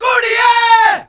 All Punjabi Vocal Pack